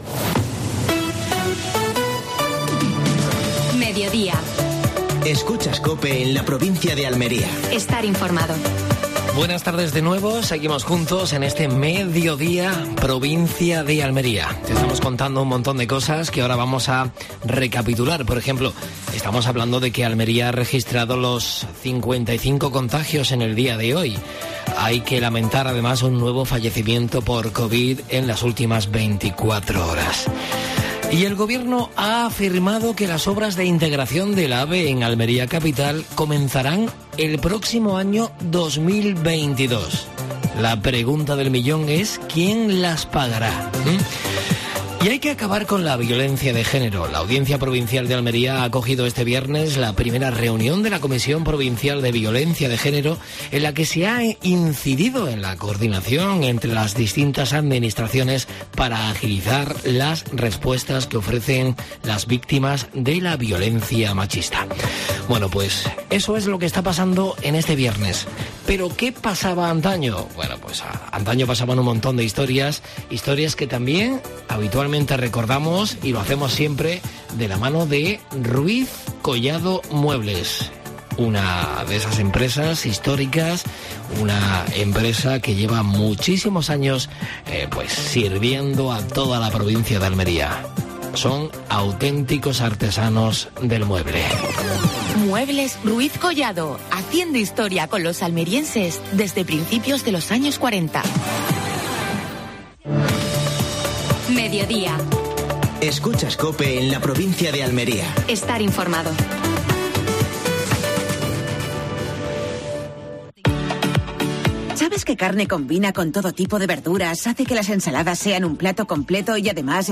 AUDIO: Actualidad en Almería.